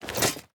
Equip_netherite4.ogg.mp3